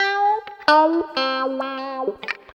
136 GTR 1 -L.wav